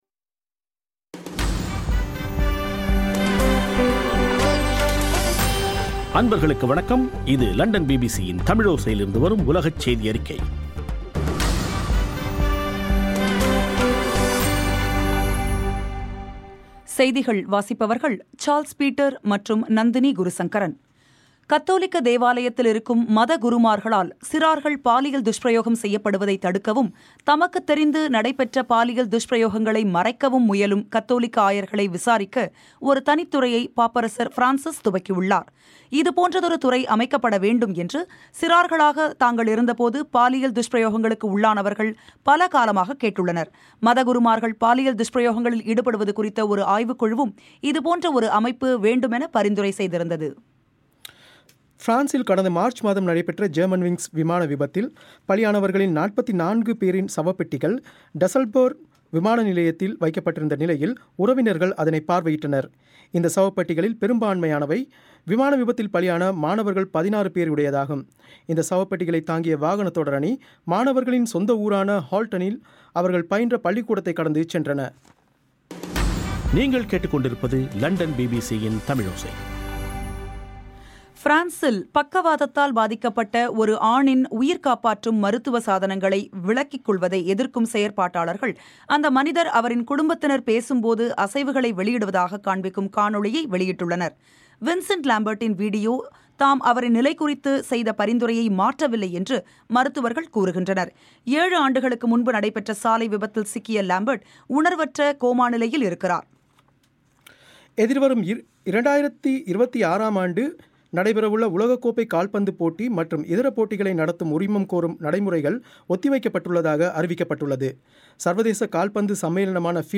ஜூன் 10 2015 பிபிசி தமிழோசையின் உலகச் செய்திகள்